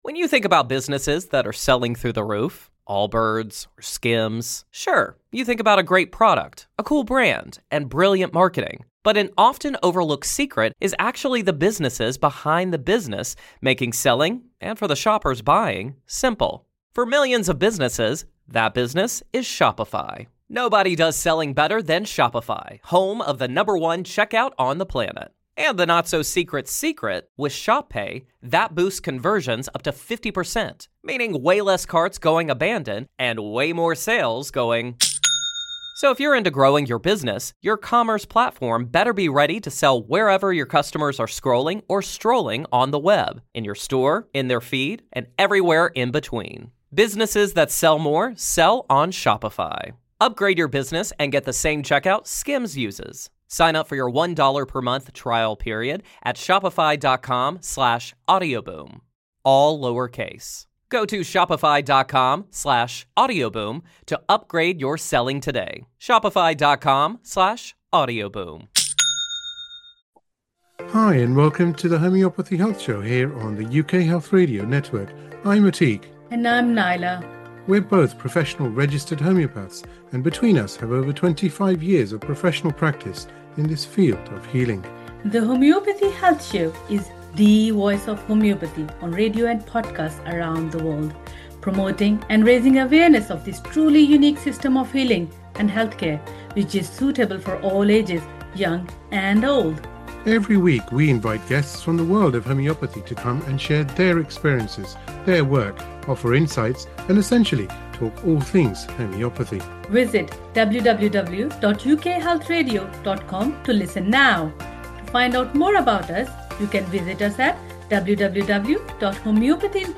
expert interviews, insightful conversations